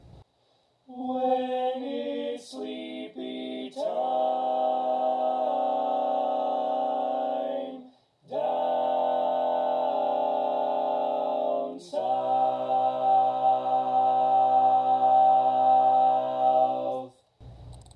Key written in: C Major
How many parts: 4
Type: Barbershop
Has a nice overtone at the end.
All Parts mix: